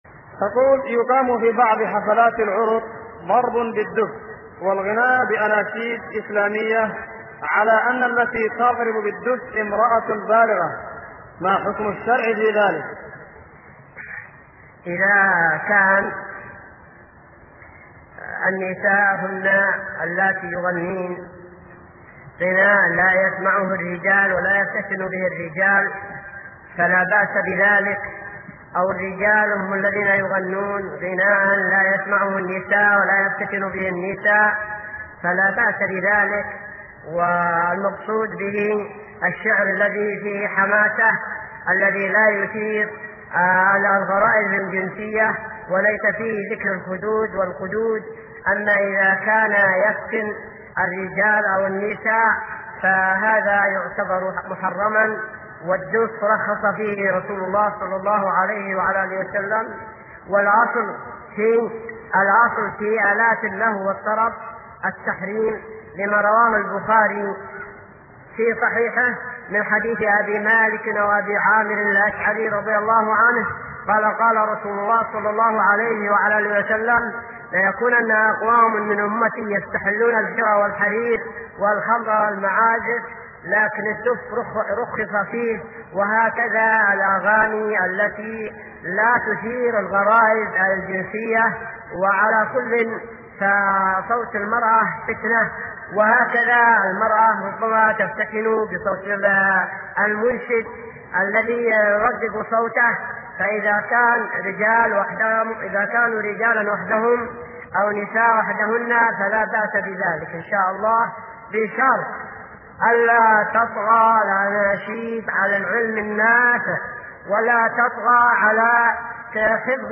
| فتاوى الشيخ مقبل بن هادي الوادعي رحمه الله
من شريط : ( نصيحة للنساء في مسجد الإيمان ) .